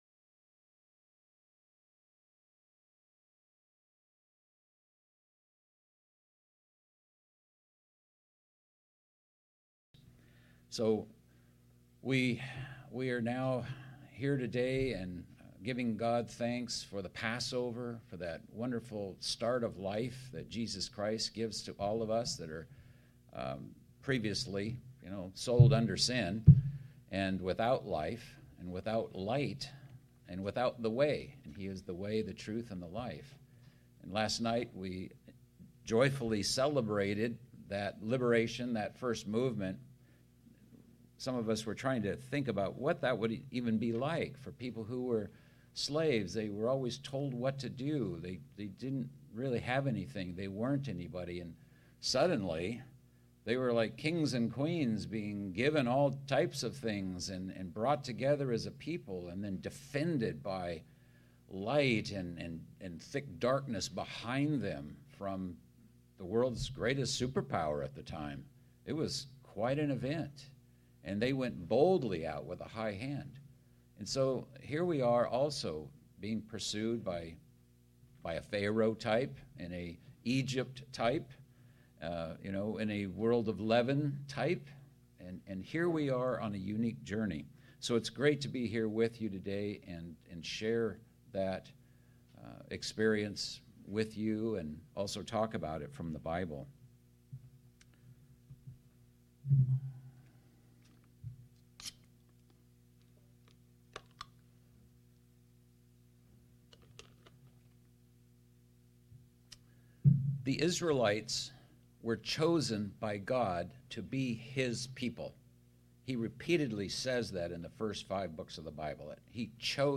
In this sermon we are given five examples of how to grow in this area.